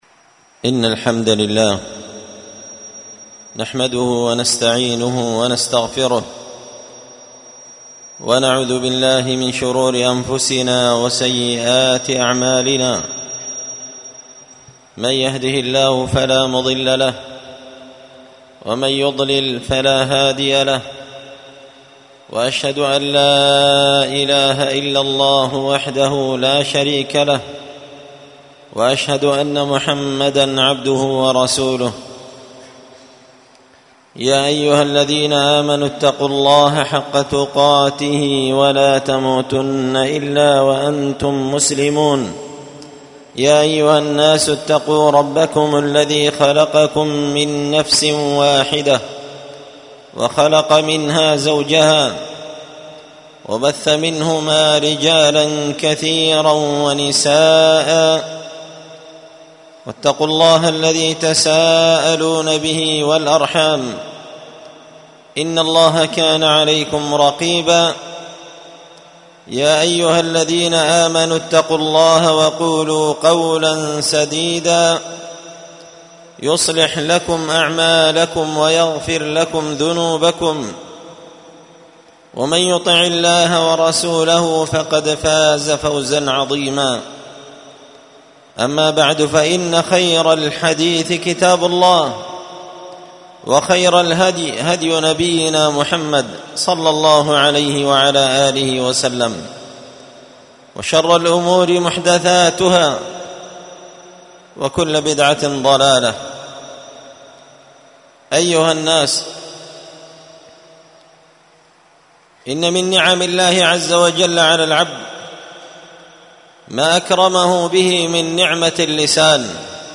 خطبة جمعة بعنوان:
ألقيت هذه الخطبة بدار الحـديـث السلفية بمـسجـد الفـرقـان قشن-المهرة-اليمن تحميل